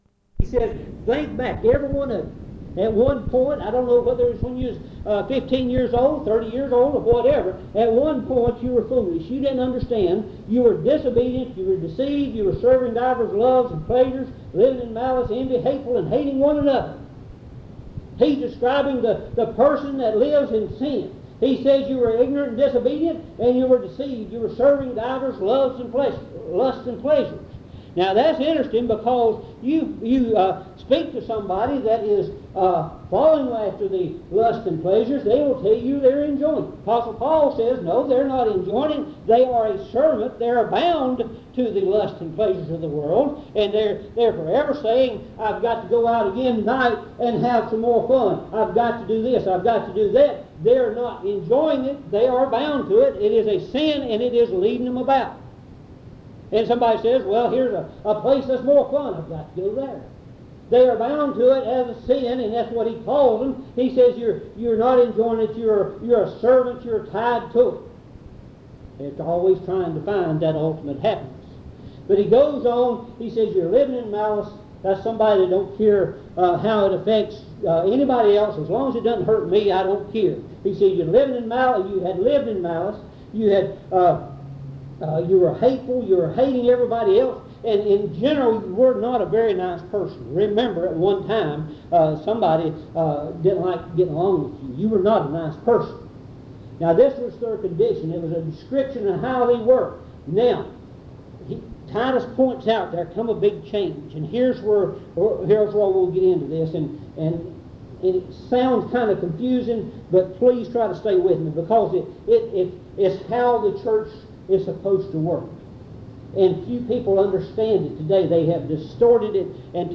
Message
at Monticello Primitive Baptist Church